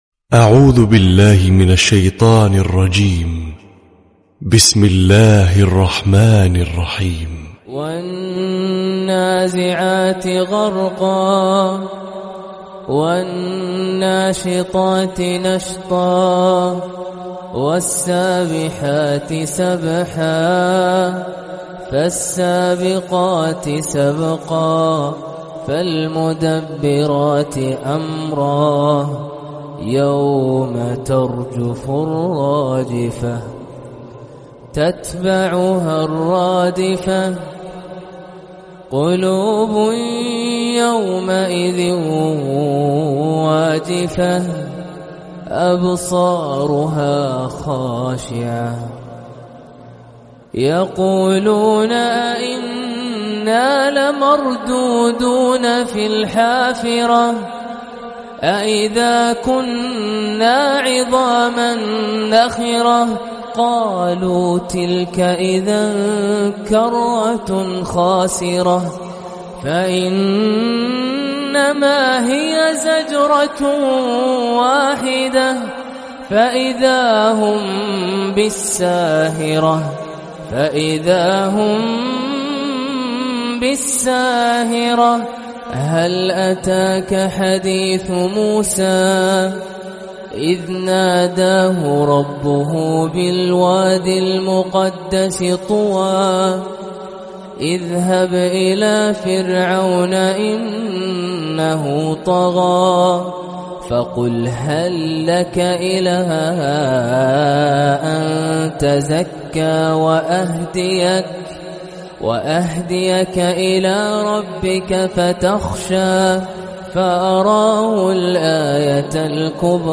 🕋🌻•تلاوة صباحية•🌻🕋